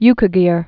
(ykə-gîr)